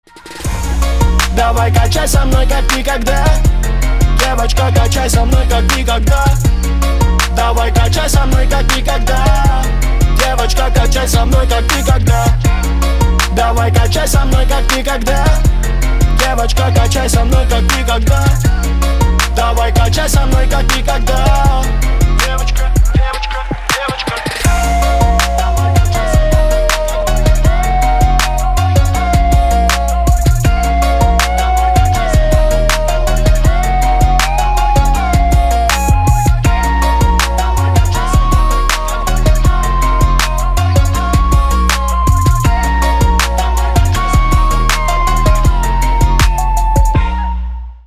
мужской вокал
Хип-хоп
мотивирующие